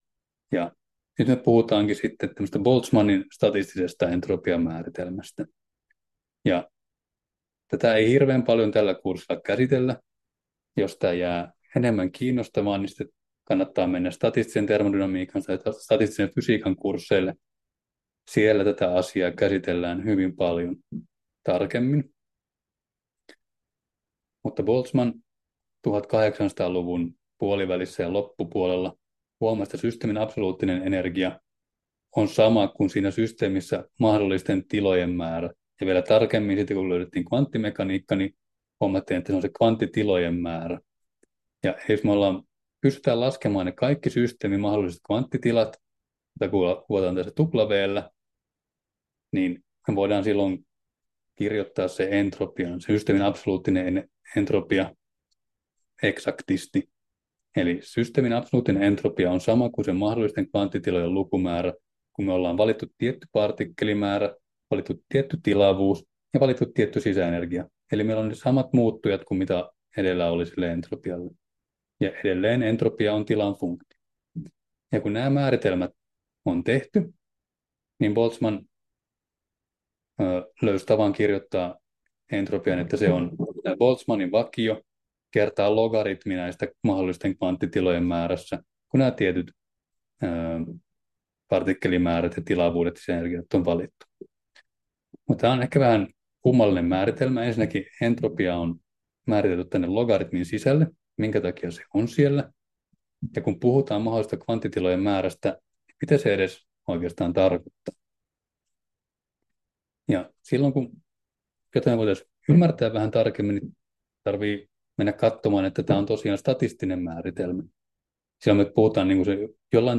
Luento 5: Entropia 5 — Moniviestin